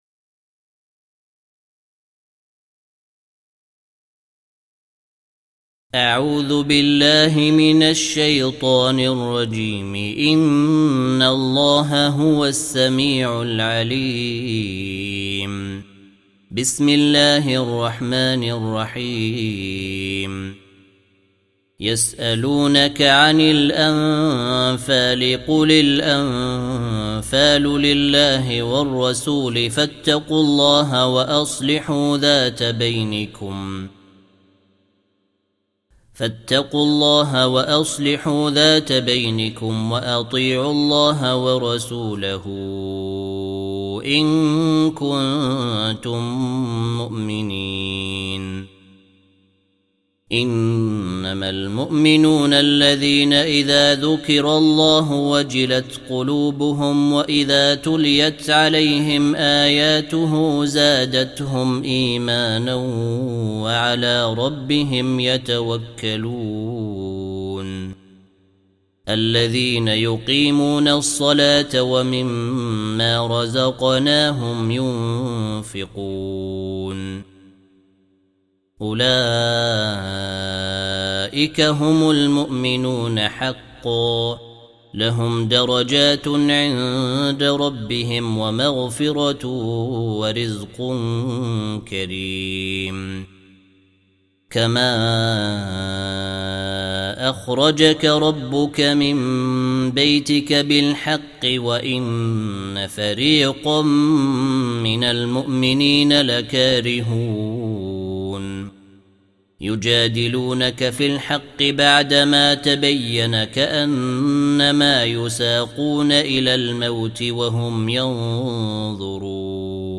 8. Surah Al-Anf�l سورة الأنفال Audio Quran Tarteel Recitation
Surah Repeating تكرار السورة Download Surah حمّل السورة Reciting Murattalah Audio for 8.